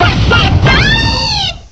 cry_not_delphox.aif